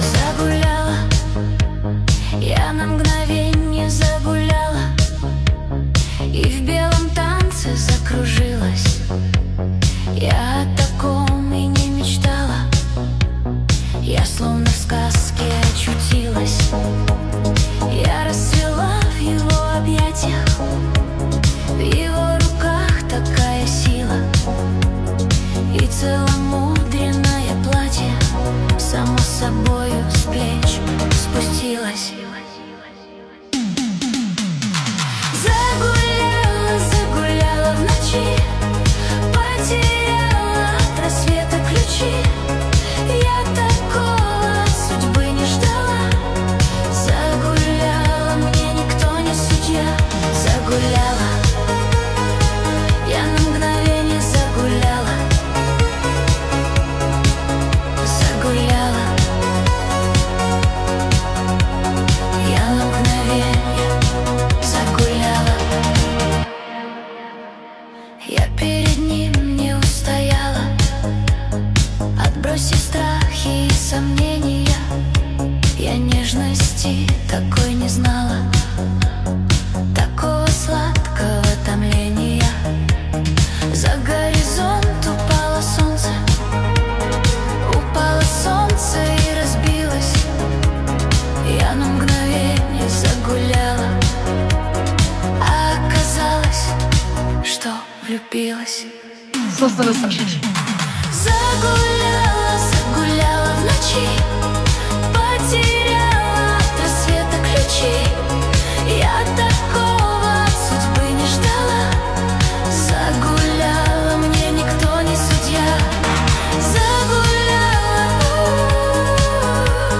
Качество: 320 kbps, stereo
Нейросеть Песни 2025, Песни Суно ИИ